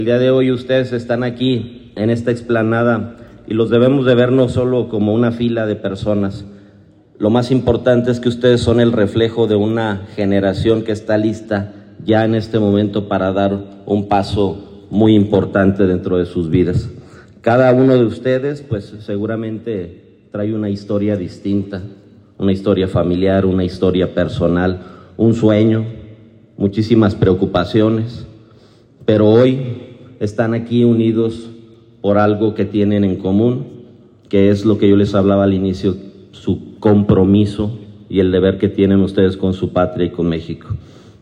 AudioBoletines
Rodolfo Gómez Cervantes, secretario del ayuntamiento